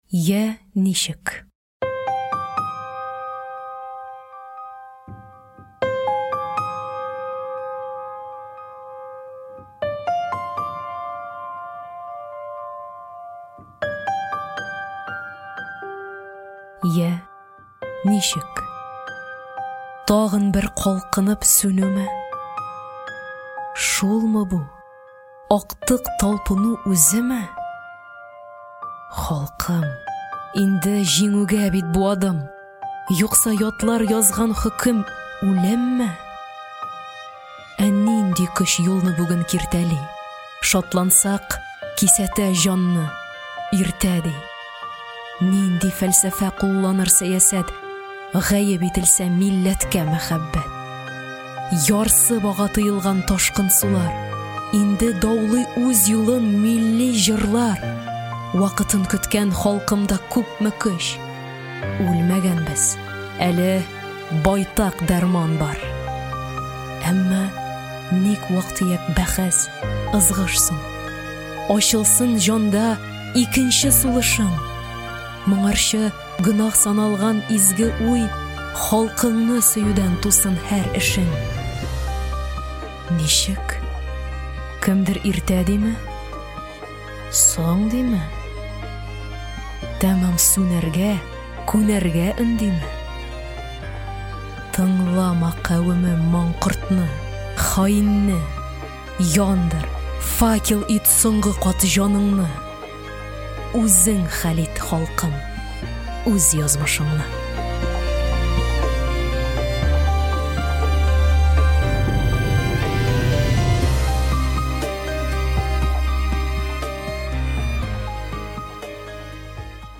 Аудиокнига Шигырьләр 3 | Библиотека аудиокниг